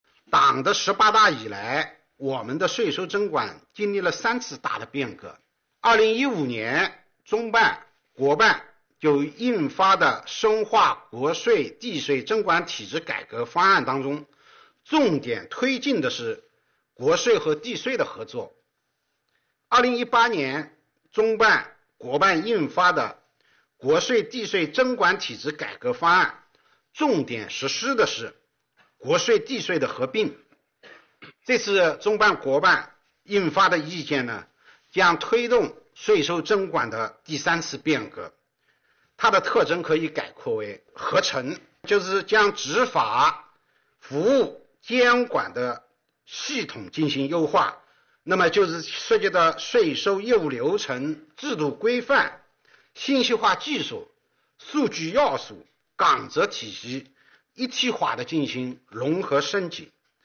3月29日，国务院新闻办公室举行新闻发布会，国家税务总局相关负责人介绍《关于进一步深化税收征管改革的意见》（以下简称《意见》）有关情况。会上，国家税务总局党委委员、副局长任荣发表示，此次出台的《意见》，将推动税收征管从合作、合并到合成的突破。